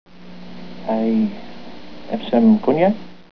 And, of course, an actor who knows how to use his voice as well as Gary Raymond can make even the oddest line sophisticated and memorable.